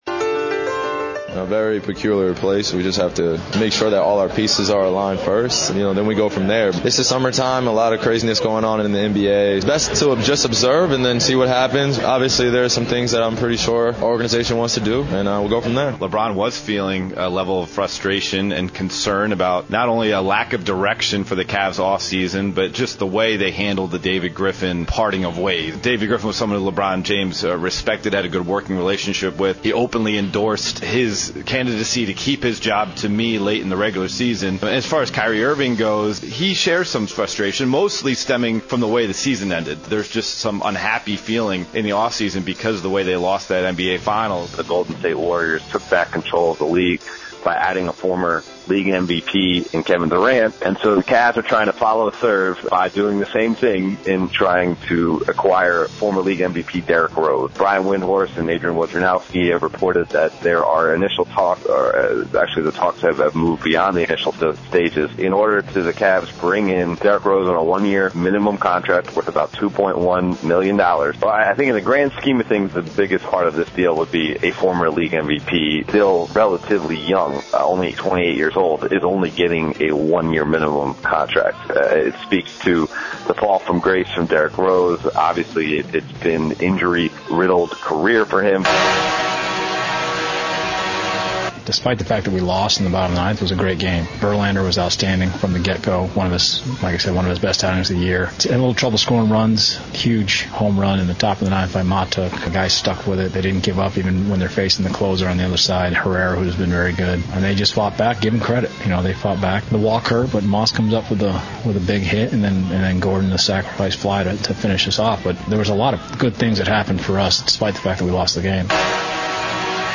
broadcasts live from Highland Meadows for Marathon Classic